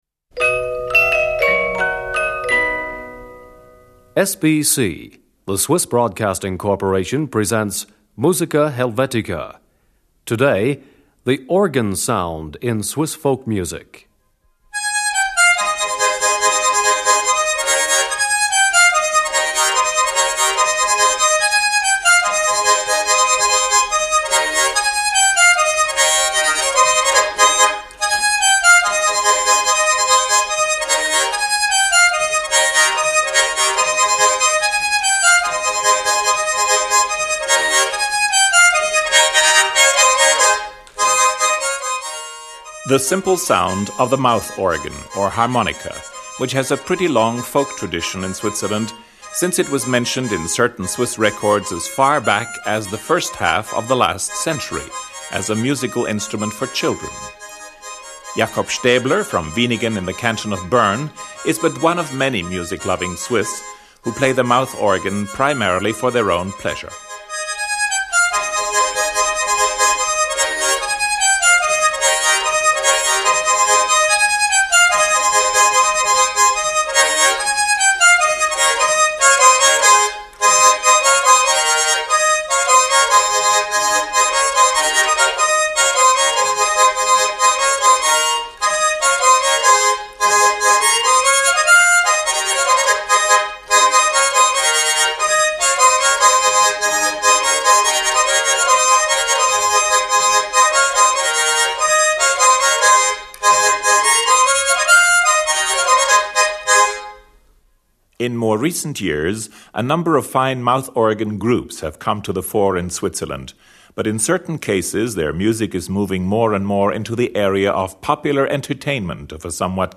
Mouth organ solo piece (Schottisch).
Mouth organ quartet.
schwyzerörgeli accordion. 5.
Schyzerörgeli duo performance.
Schwyzerörgeli band performance.
Accordion duo performance.
Traditional dance.
Traditional folk song.